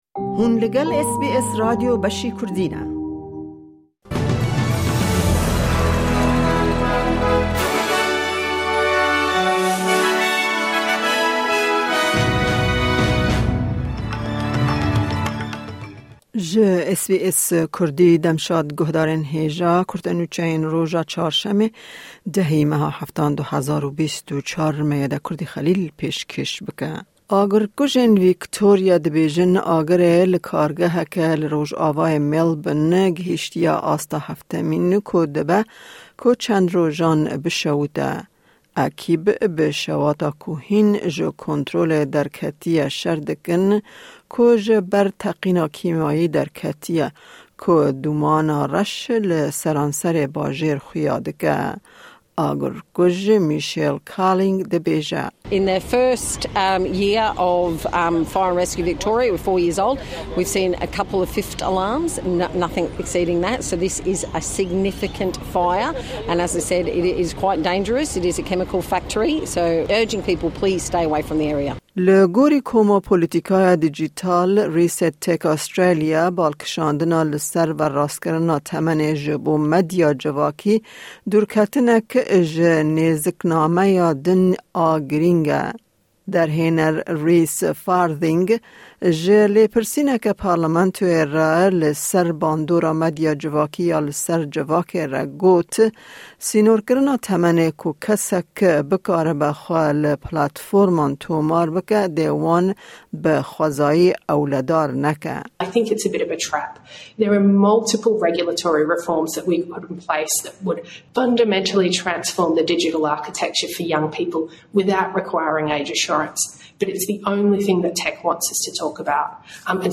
Kurte Nûçeyên roja Çarşemê 10î Tîrmeha 2024